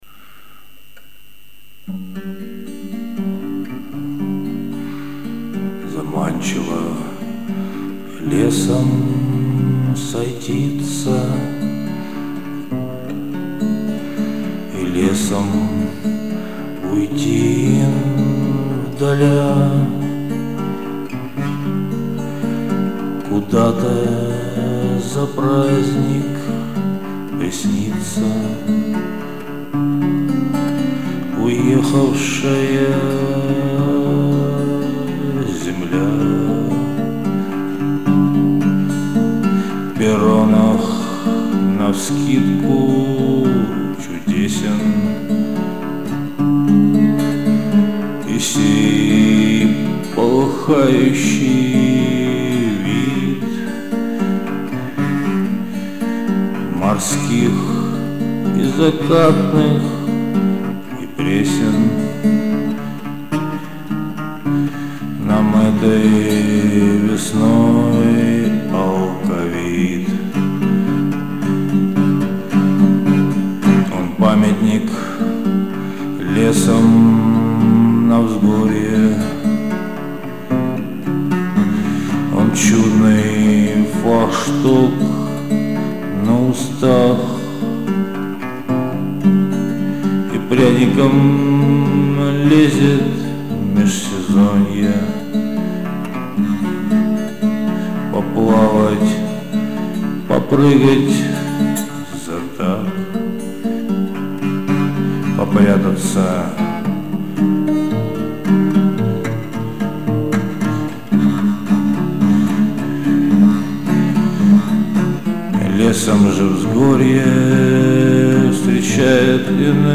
Достаточно заунывно, все как я люблю...
Стихи читать невозможно, а гитара и непонятный вокал даже и ничего.